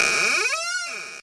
Grincement de porte.mp3